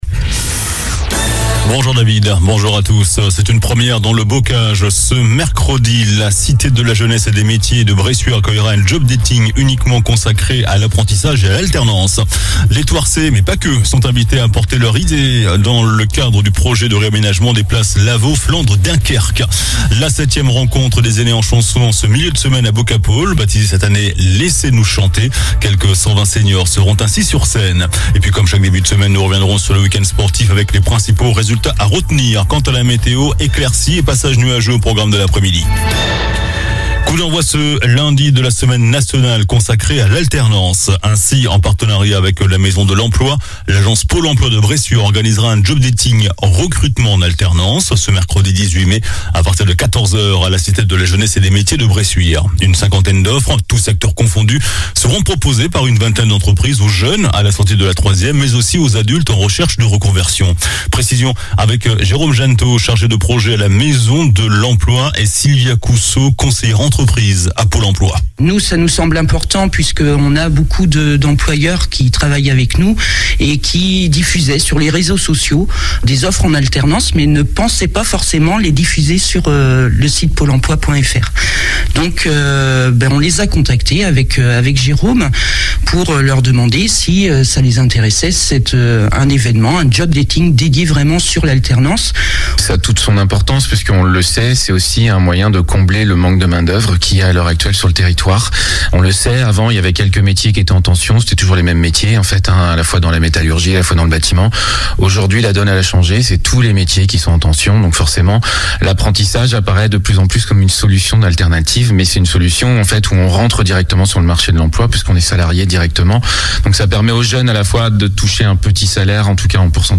JOURNAL DU LUNDI 16 MAI ( MIDI )